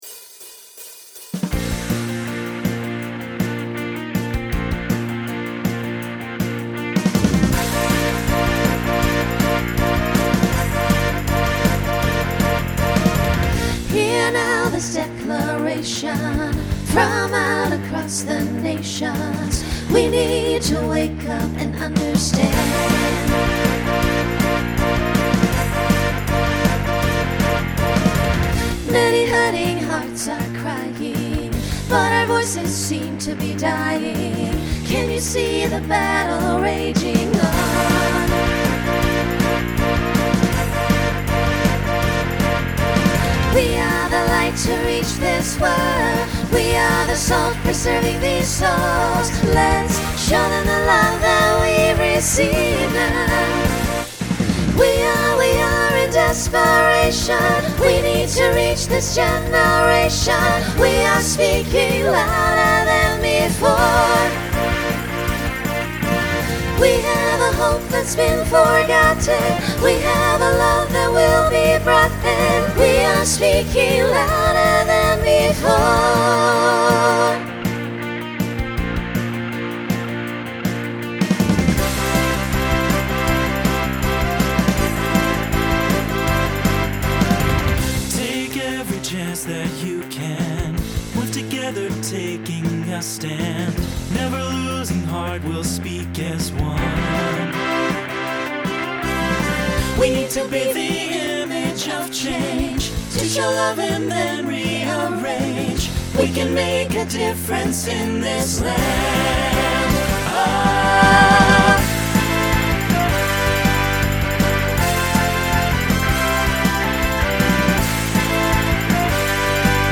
Begins with a treble trio to facilitate costume change.
Genre Rock
Transition Voicing Mixed